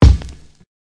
Kick (5).wav